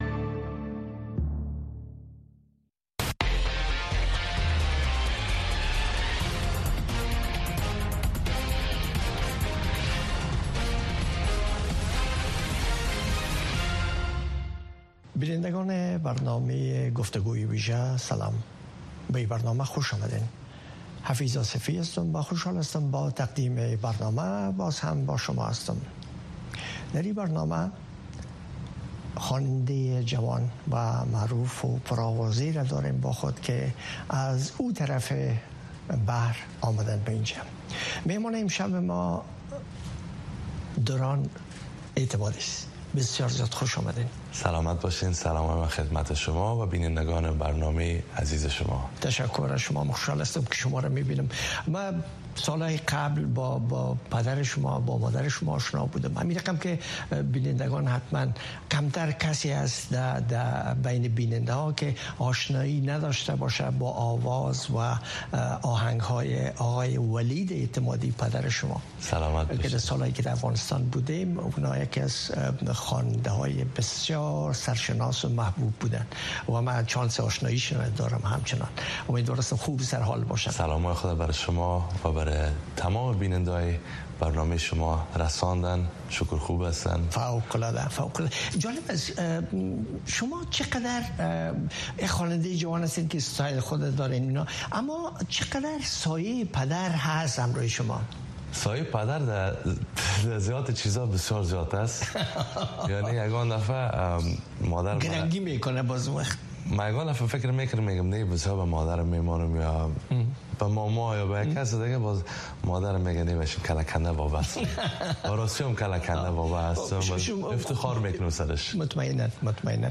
گفتگوی ویژه